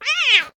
cat_meow_strong1.ogg